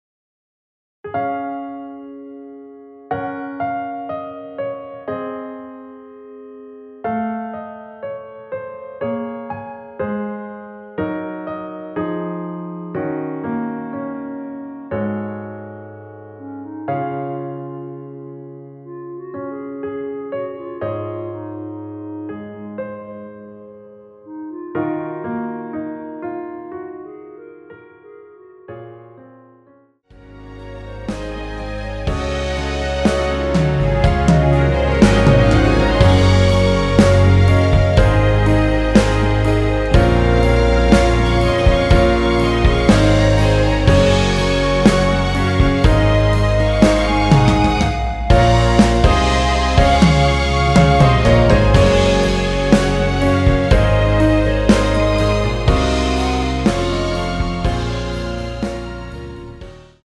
남성분이 부르실수 있는 멜로디 포함된 MR 입니다.(미리듣기 참조)
Db
앞부분30초, 뒷부분30초씩 편집해서 올려 드리고 있습니다.
중간에 음이 끈어지고 다시 나오는 이유는